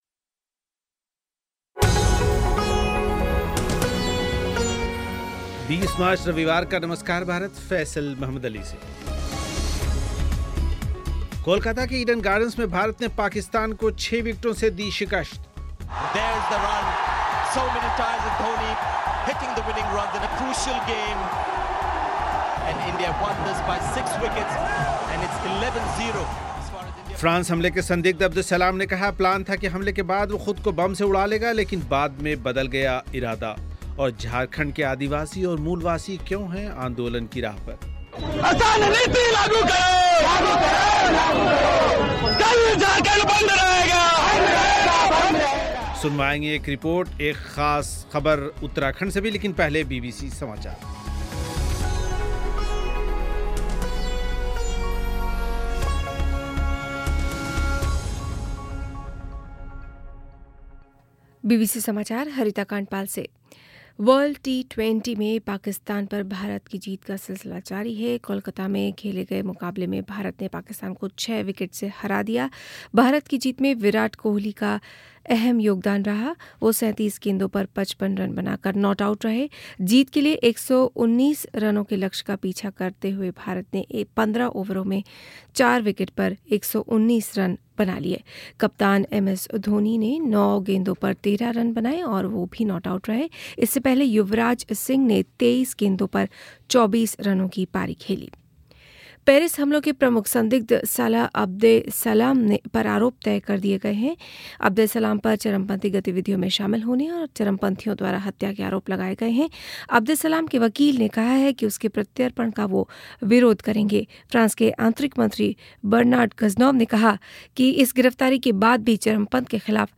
एक रिपोर्ट, साथ ही बातचीत कुछ फैन्स के साथ